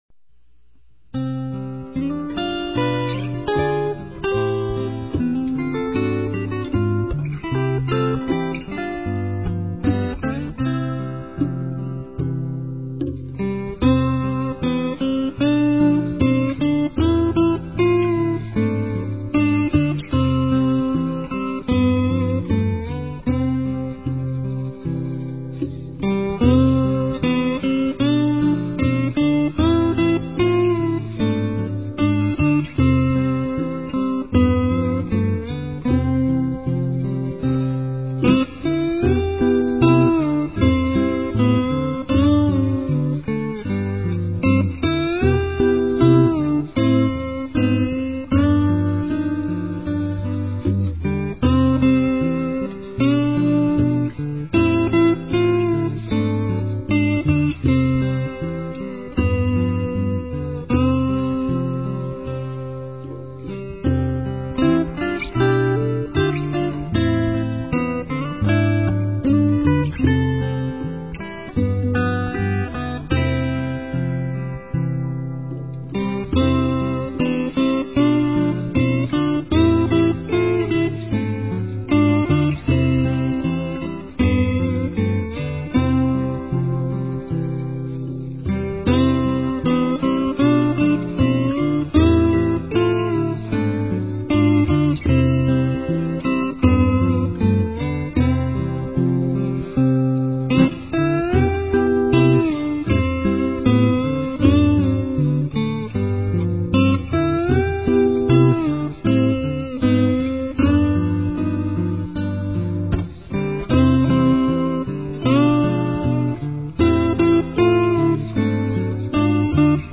本来、唄付きの曲をアコースティックギターによるインストにしたものです。